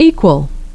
'equal <)),